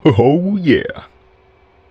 ohyeah.wav